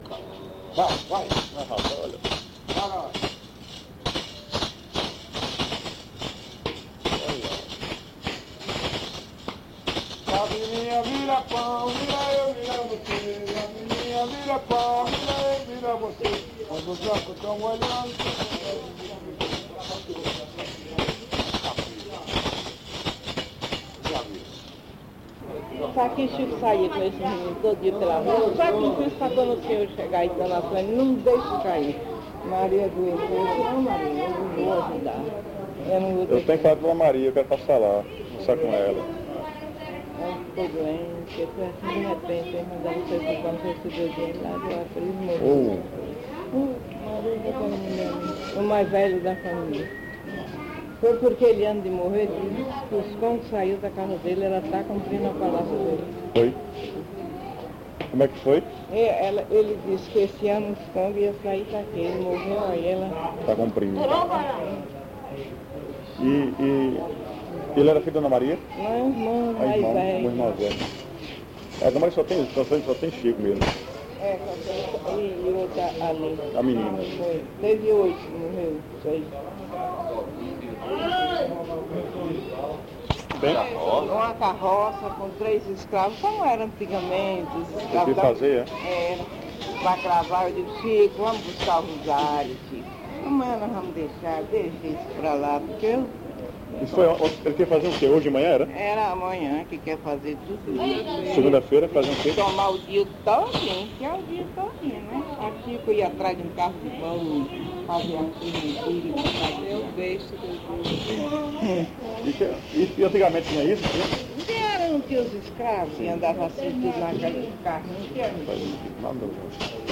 Série Brincantes - Festa de Nossa Senhora do Rosário
A música dos congos é produzida pelos maracás e marcada pelos vigorosos passos da dança, acompanhados de viola. [...] Tanto o canto como a fala [...] é dita em português estropiado e palavras soltas que parecem ser de dialeto africano, de sentido inteiramente esquecido.